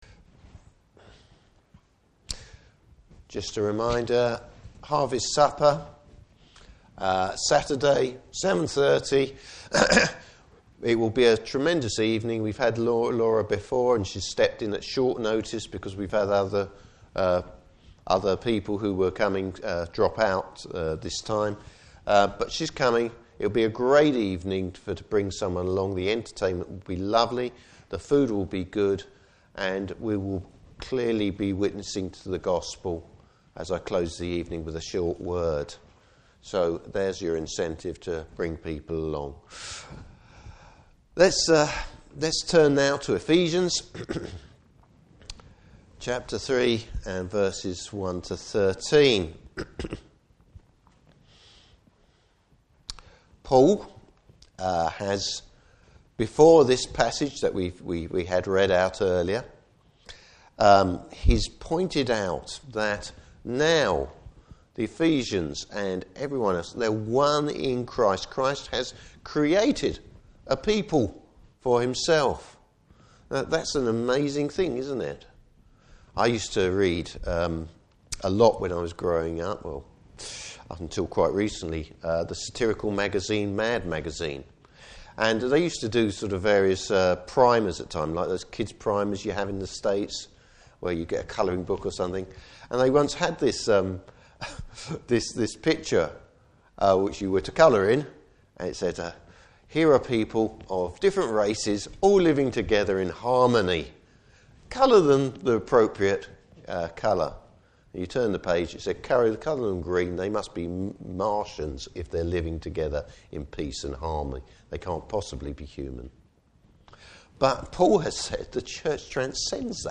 Service Type: Morning Service Bible Text: Ephesians 3:1-13.